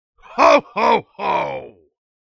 Saxton Hale responses
Licensing This is an audio clip from the game Team Fortress 2 .